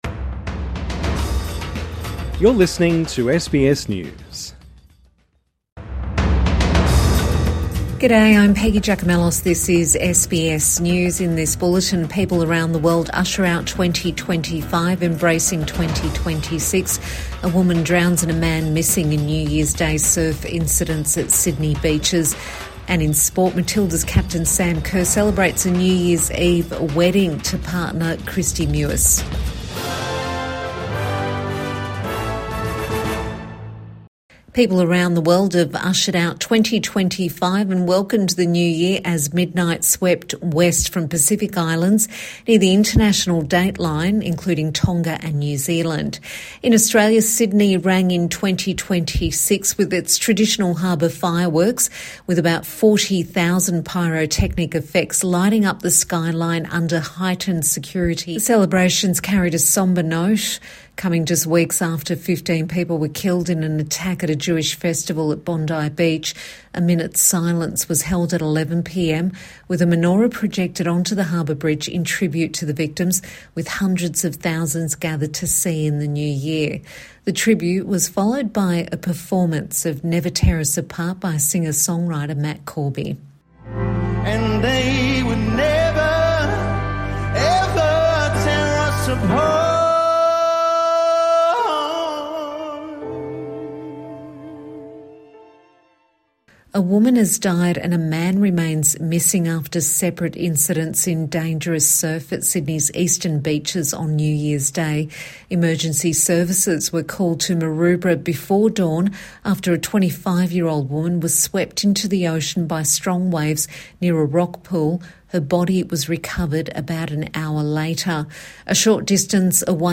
The World welcomes a New Year | Midday News Bulletin 1 January 2026